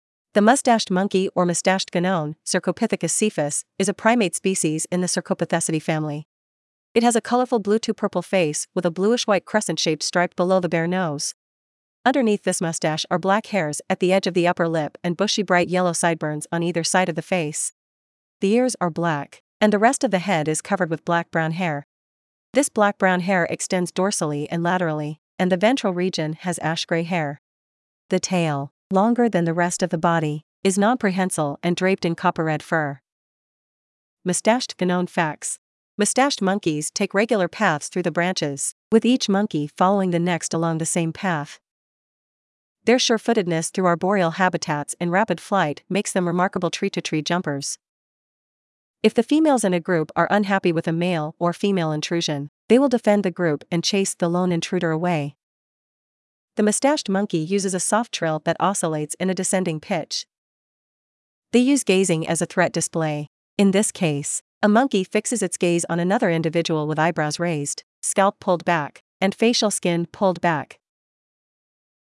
Moustached Guenon
• The moustached monkey uses a soft trill that oscillates in a descending pitch.
moustached-guenon.mp3